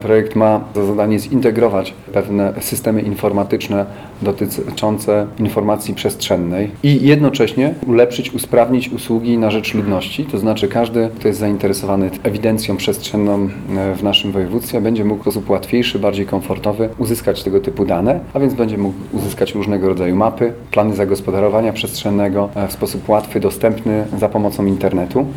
– mówił podczas konferencji marszałek województwa – Olgierd Geblewicz.